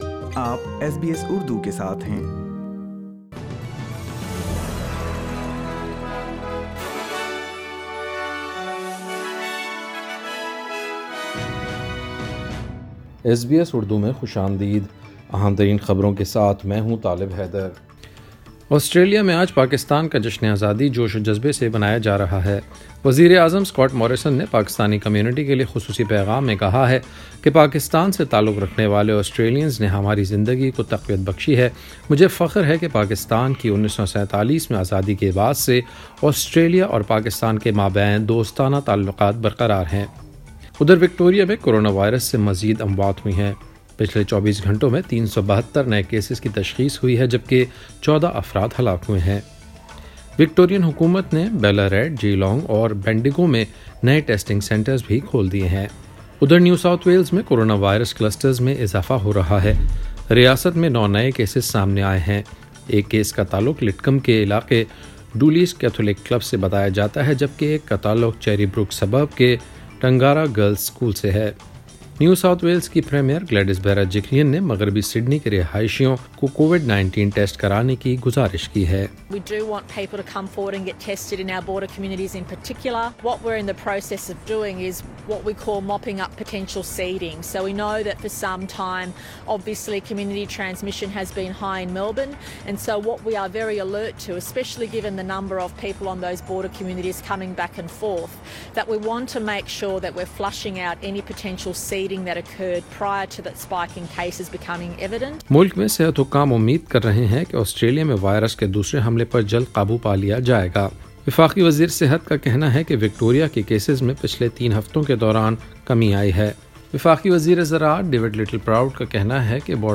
ایس بی ایس اردو خبریں 14 اگست 2020